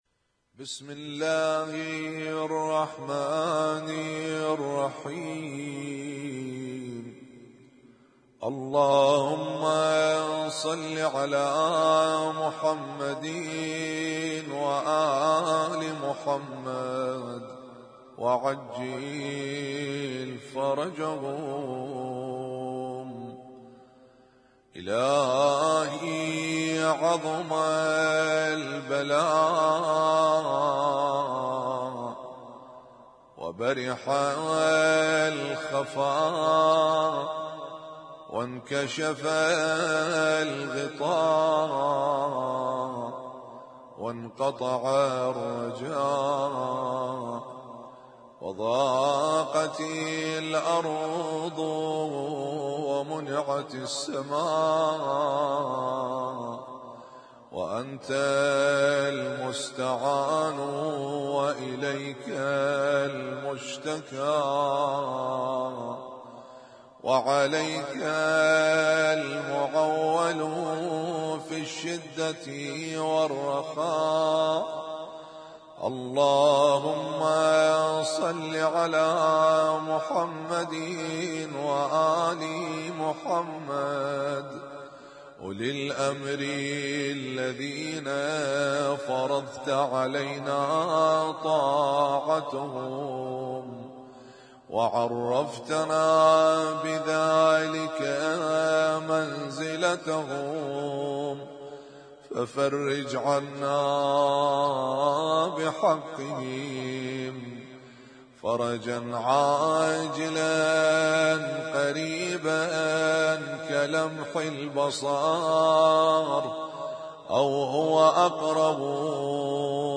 اسم التصنيف: المـكتبة الصــوتيه >> الادعية >> الادعية المتنوعة